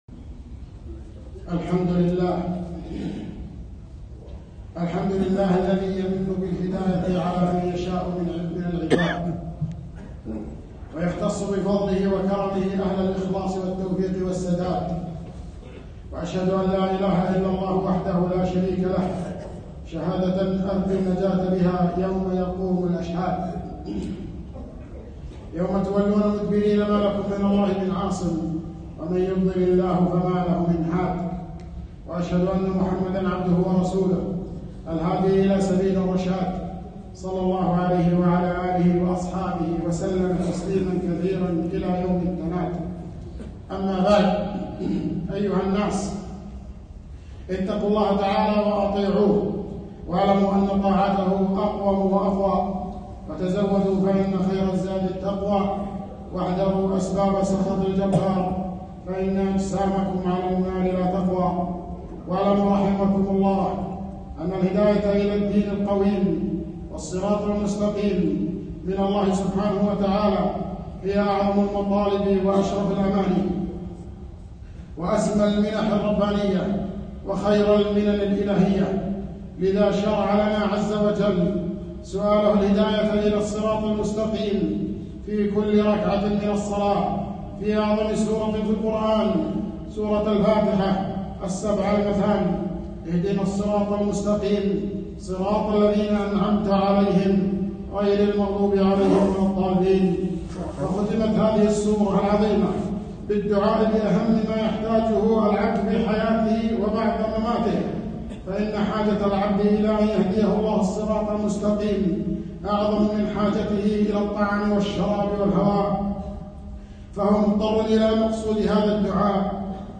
خطبة - أسباب الهداية